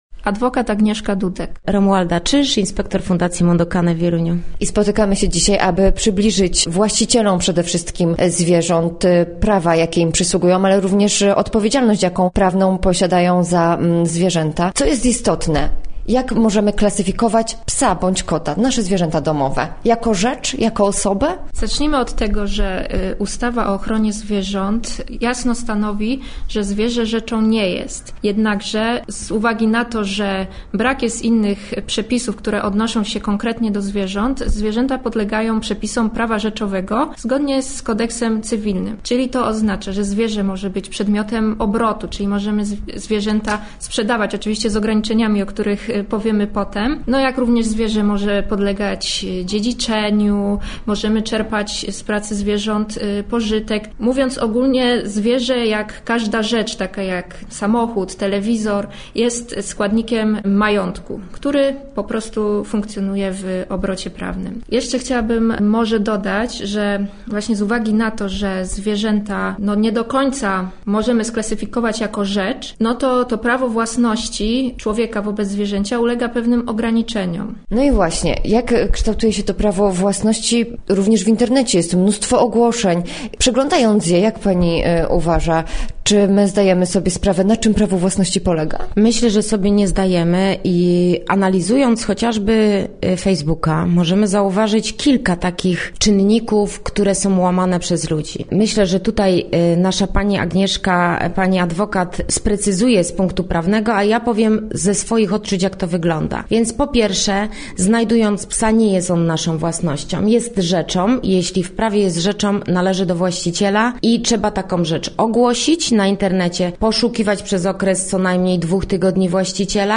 Nie tylko miłośników czworonogów zachęcamy do wysłuchania rozmowy